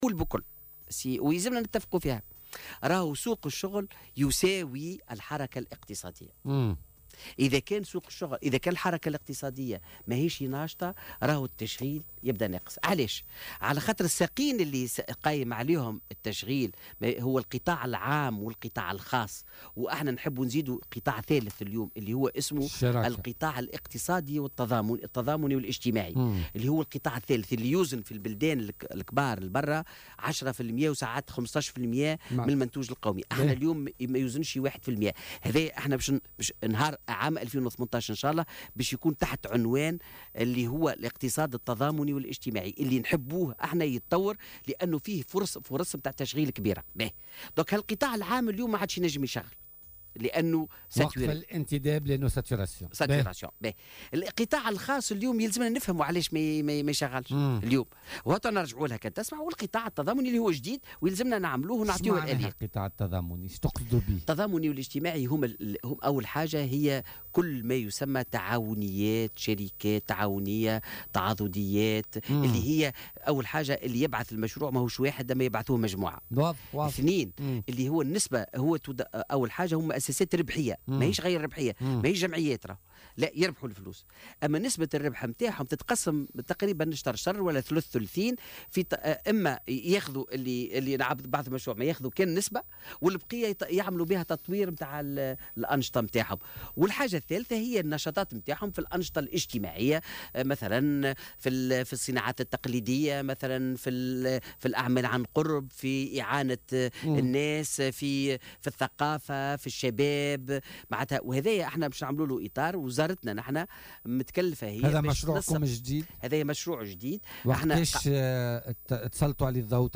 قال فوزي عبد الرحمان وزير التشغيل والتكوين المهني ضيف بولتيكا اليوم الخميس 9 نوفمبر 2017 أن الوزارة ستقوم بوضع الإطار القانوني للقانون التضامني و الاجتماعي قبل موفى السنة الحالية وستضع البرامج والآليات الكفيلة بتطبيقه على حد قوله.